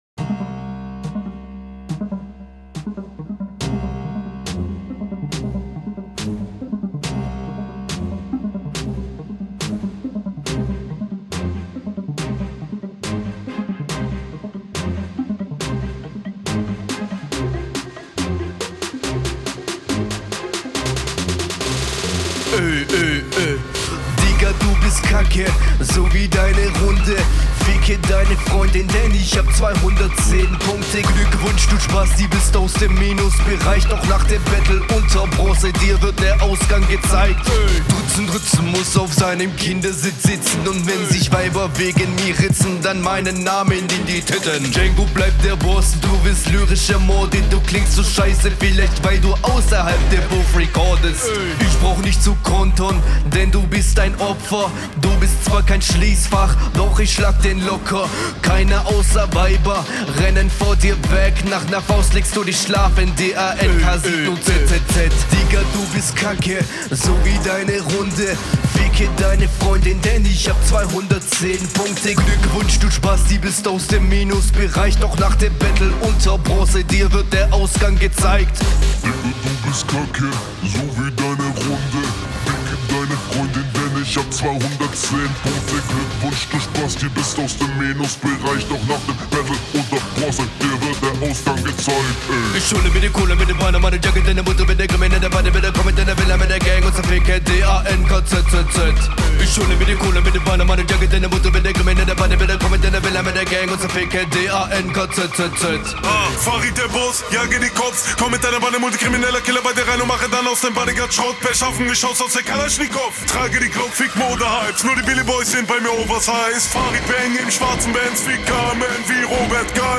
Flow: flow ist VIEEEEL besser als bei'm gegner und stimmen einsatz ist auch völlig ok …